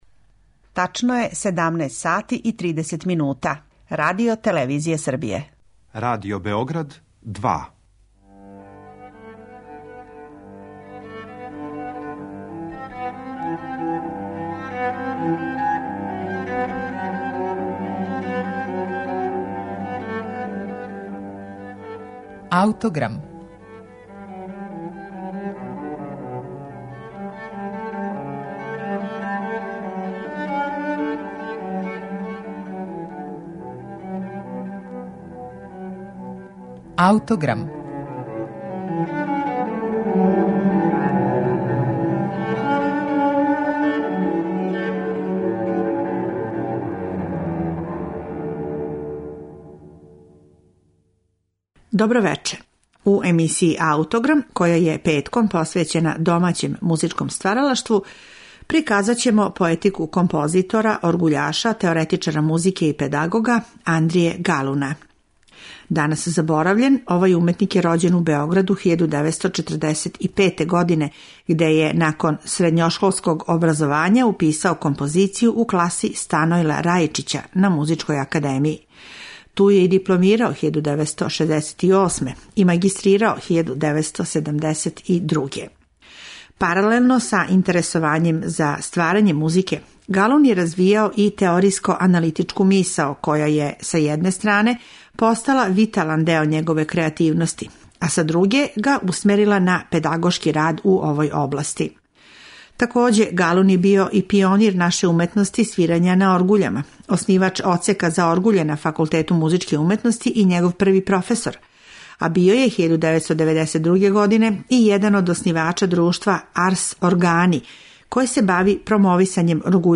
Емитоваћемо архивски снимак
клавир и оркестар